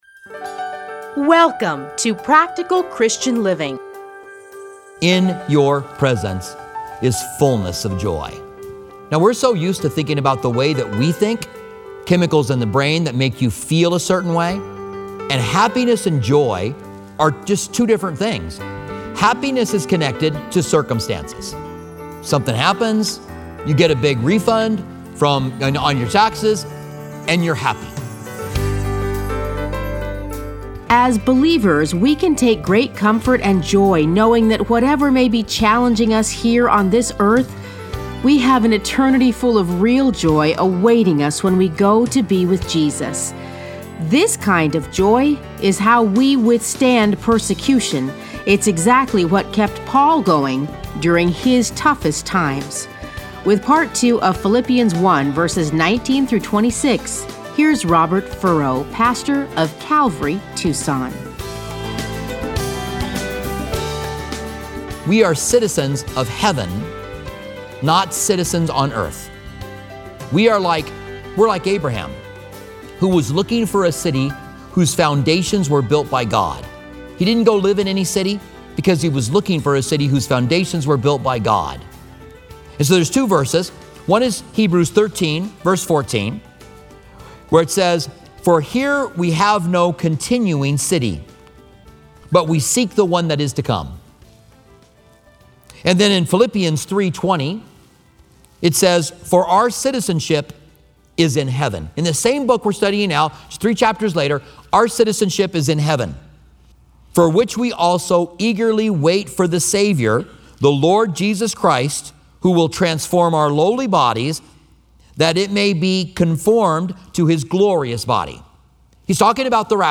Listen to a teaching from Philippians 1:19-26.